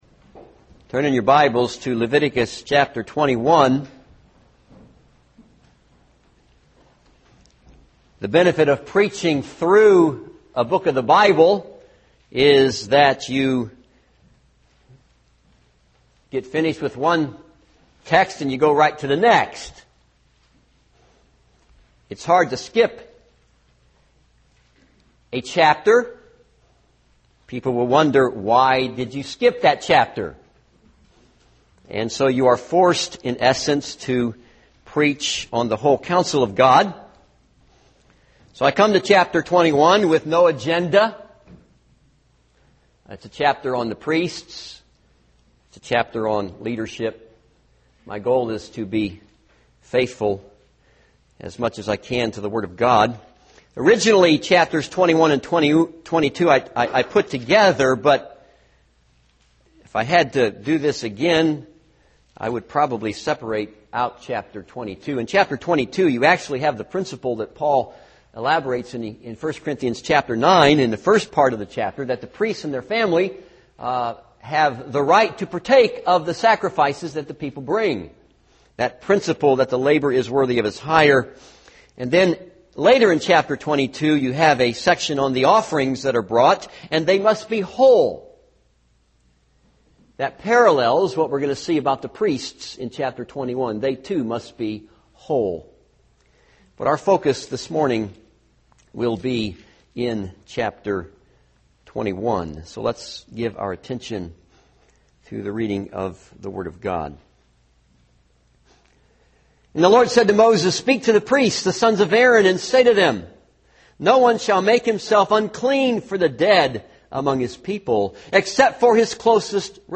This is a sermon on Leviticus 21.